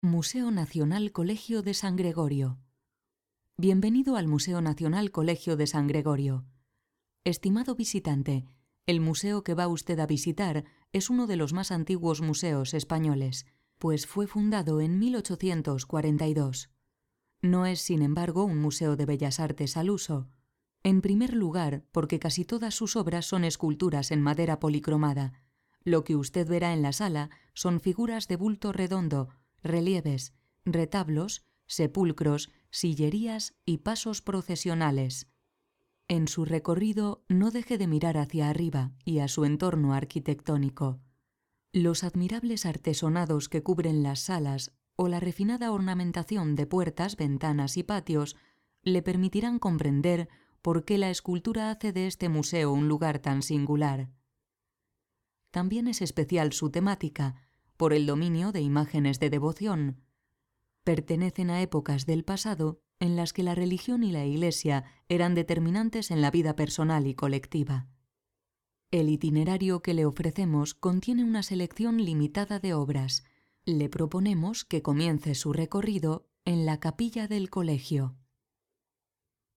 Voz media.
Sprechprobe: eLearning (Muttersprache):